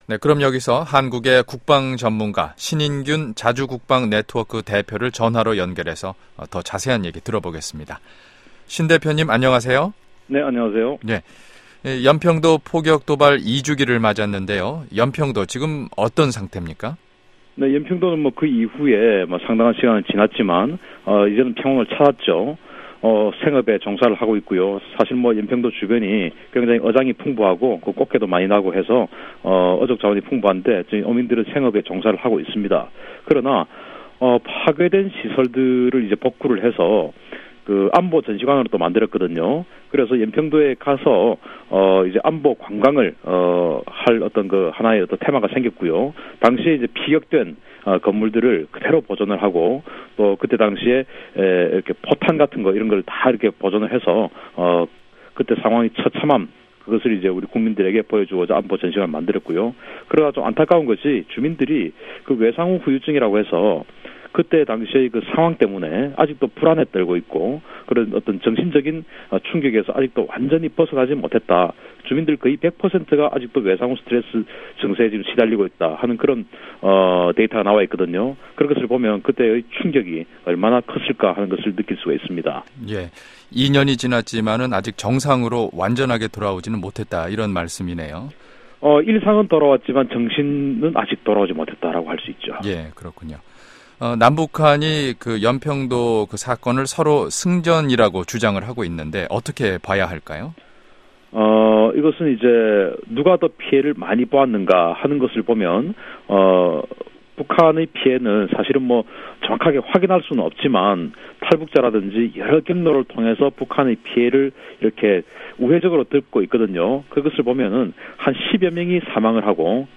[인터뷰]